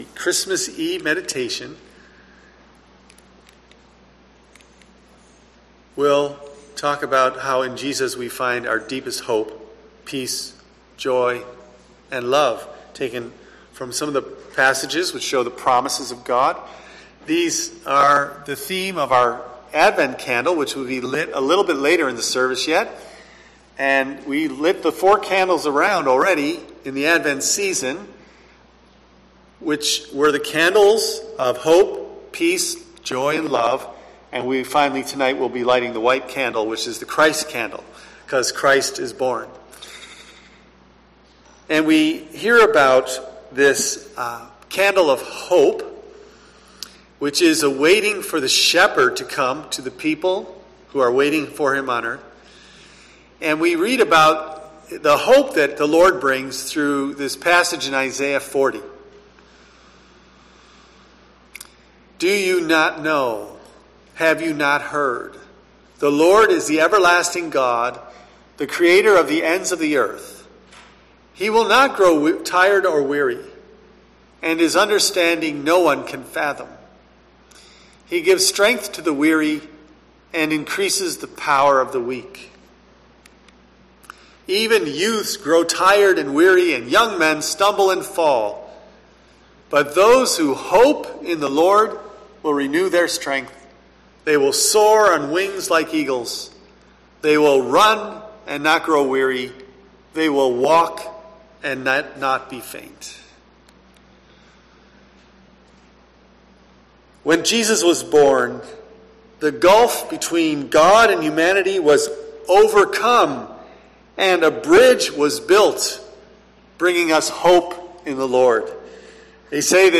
Join us listening to a special Christmas Eve service.